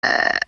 burp1.wav